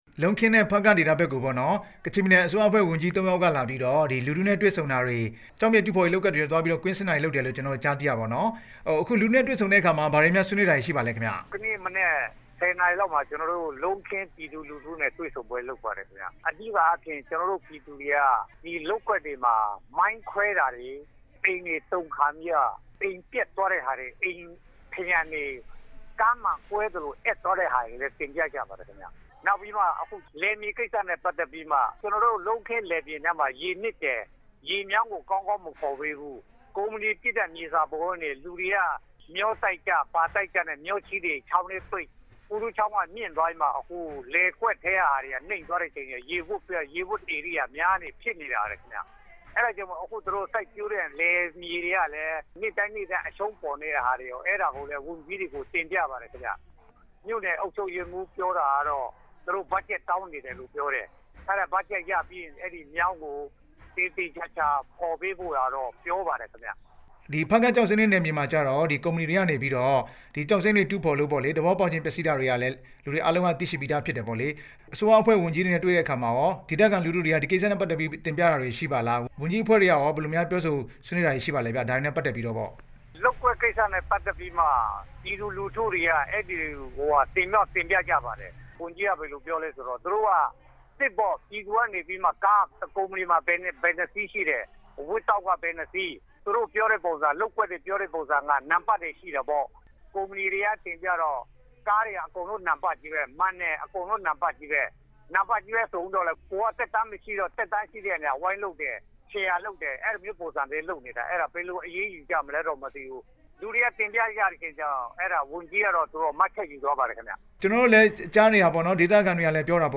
လုံးခင်းရွာနဲ့ ဖားကန့်မြို့ လူထုတွေ့ဆုံပွဲအကြောင်း မေးမြန်းချက်